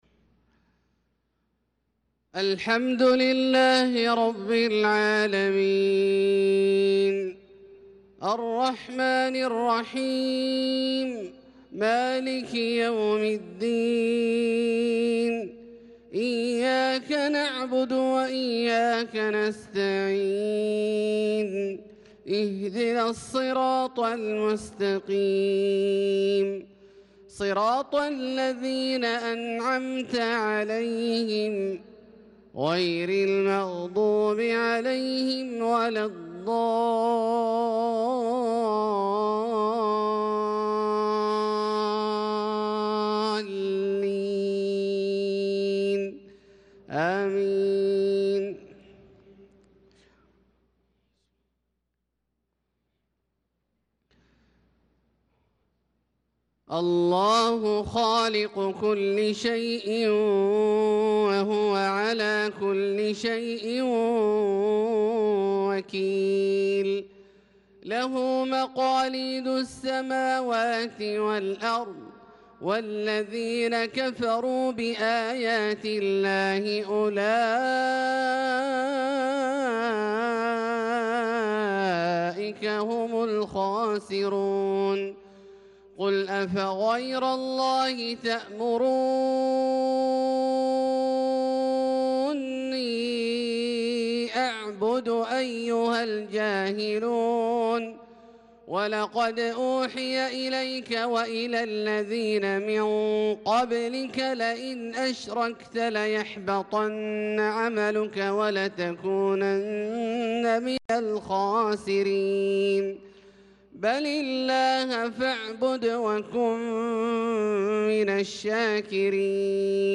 صلاة الفجر للقارئ عبدالله الجهني 19 ذو الحجة 1445 هـ
تِلَاوَات الْحَرَمَيْن .